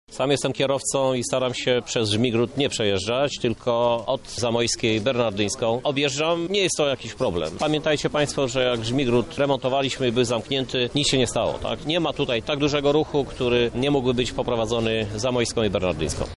– tłumaczy Krzysztof Żuk, prezydent miasta.